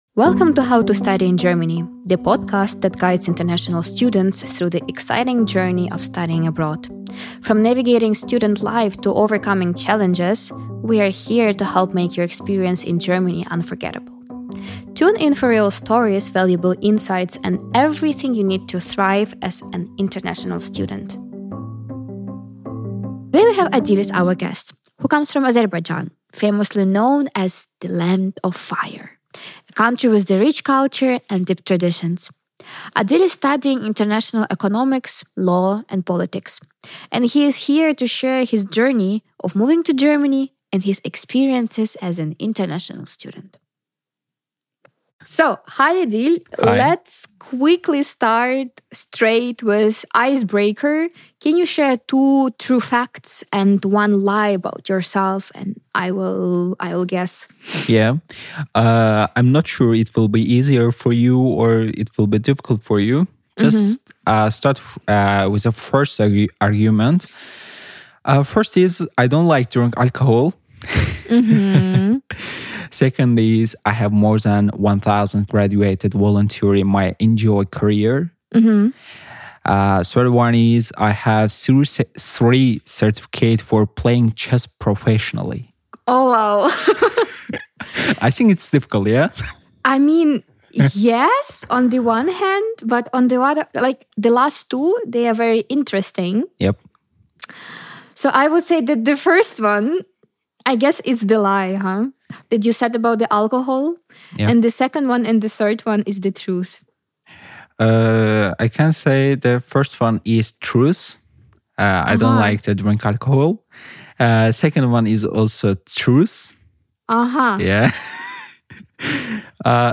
In this episode, he shares his journey of relocating to Germany, adapting to a new academic system, and navigating life as an international student. We also discuss his passion for migration policy, cultural differences, and his advice for students considering studying in Germany. Tune in for an insightful and inspiring conversation!